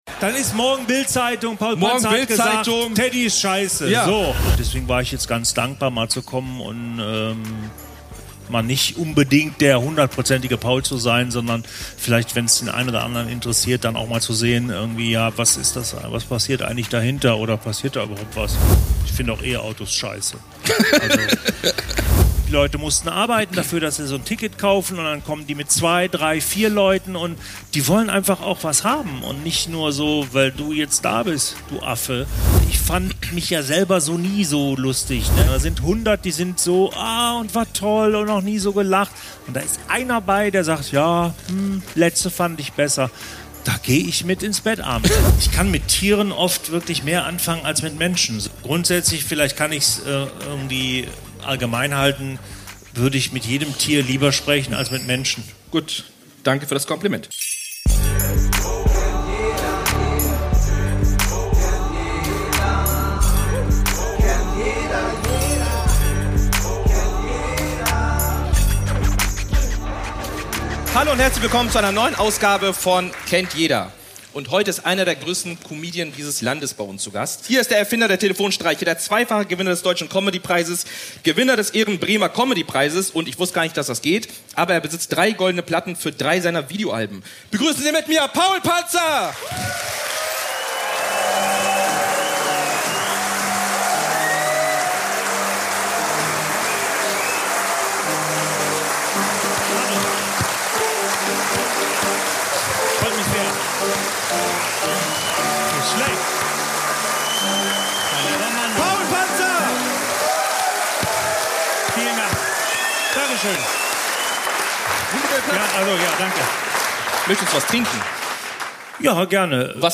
Zum ersten Mal lernt man den Menschen hinter Paul Panzer wirklich kennen: Dieter Tappert spricht über seine Karriere, den Spagat zwischen Kunstfigur und Privatleben – und darüber, wie es ist, seit Jahrzehnten in der Öffentlichkeit zu stehen. Diese Folge ist gleich in mehrfacher Hinsicht besonders: Sie wurde live vor Publikum aufgezeichnet – und Dieter hat sich nicht nur meinen Fragen gestellt, sondern auch denen der Zuschauerinnen und Zuschauer. Natürlich darf der Humor nicht fehlen: Im Spiel „Blind Ranking“ musste Paul Panzer spontan seine Comedy-Kollegen auf einer Skala von 1 bis 10 bewerten – ohne zu wissen, wer als Nächstes kommt.